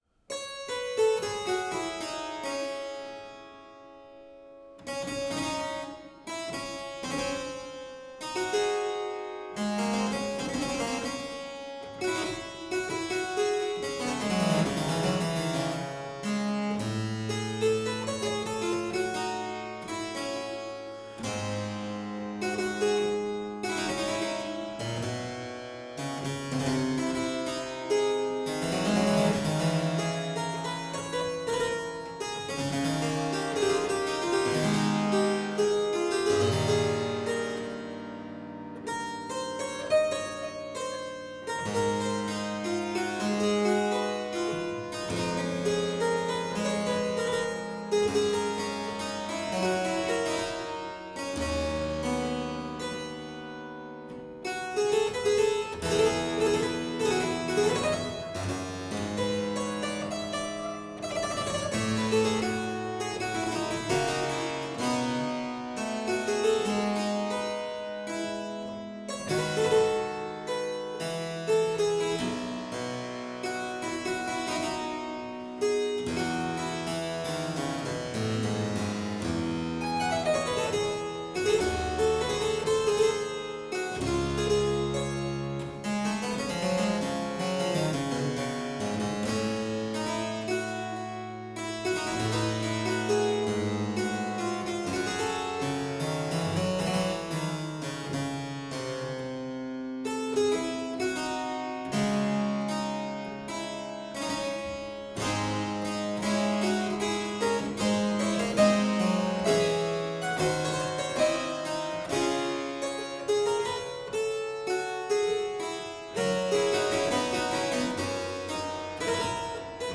harpsichordist and conductor
The first pr�lude , a substantial and very beautiful piece, is anonymous.
It is thinner in texture, lighter in its use of dissonance, more songlike and looser in gesture; and its middle section is hardly imitative at all; it feels much more like organ music of the time.
The piece bears all the attributes of a Tombeau : first of all, its pervading sense of melancholy.